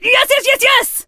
max_lead_vo_01.ogg